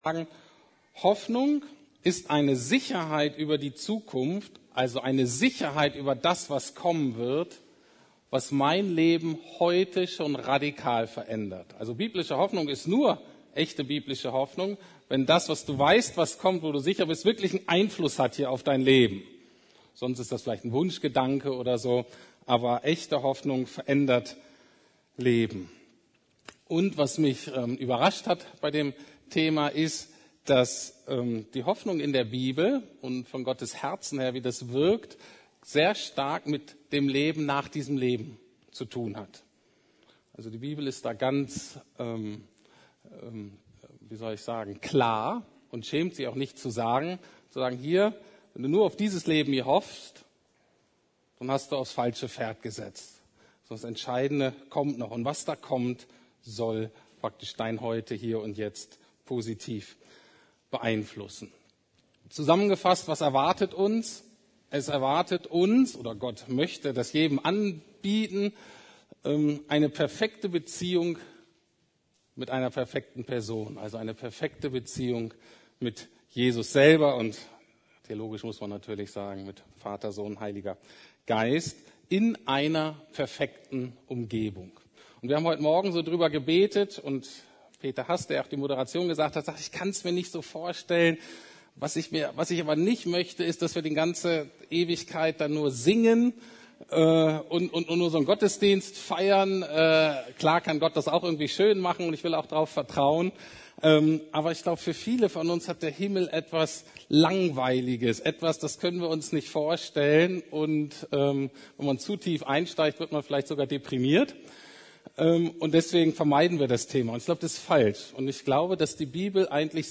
Der Gott der Hoffnung - Teil 2 ~ Predigten der LUKAS GEMEINDE Podcast